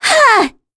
Talisha-Vox_Casting4_kr.wav